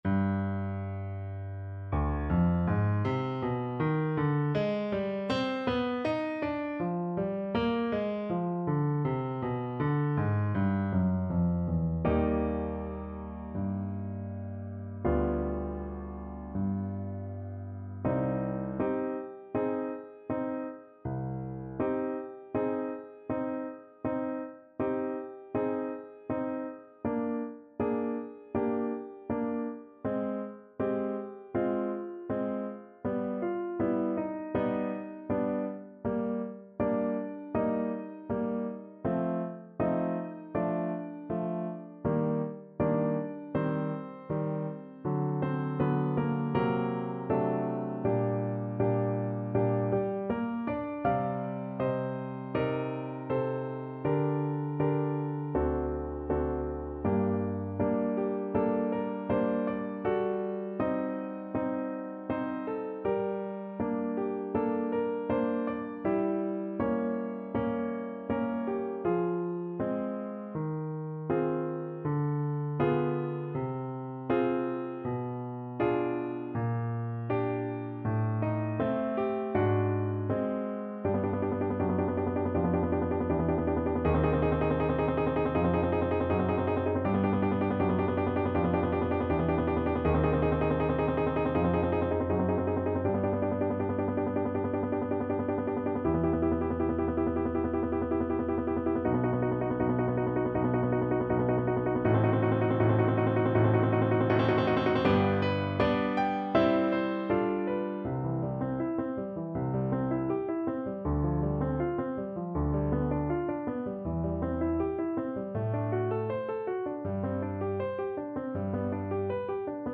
Play (or use space bar on your keyboard) Pause Music Playalong - Piano Accompaniment Playalong Band Accompaniment not yet available reset tempo print settings full screen
C minor (Sounding Pitch) G minor (French Horn in F) (View more C minor Music for French Horn )
~ = 100 Molto moderato =80
4/4 (View more 4/4 Music)
Classical (View more Classical French Horn Music)